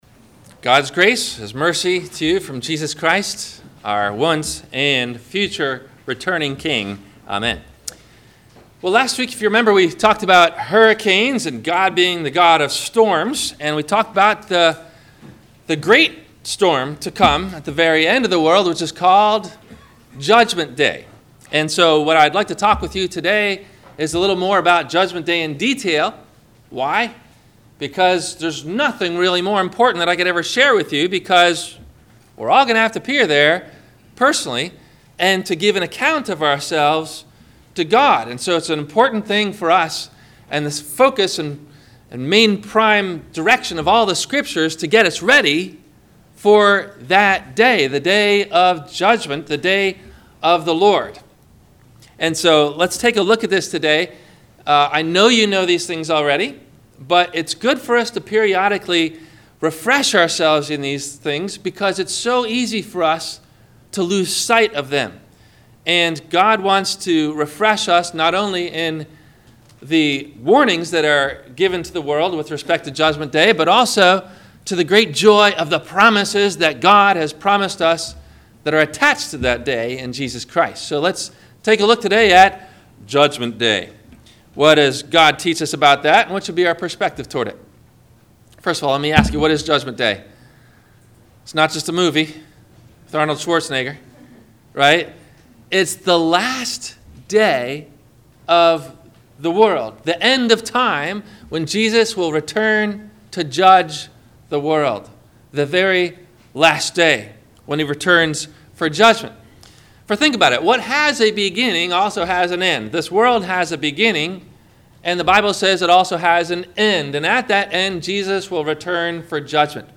Judgement Day - Sermon - September 24 2017 - Christ Lutheran Cape Canaveral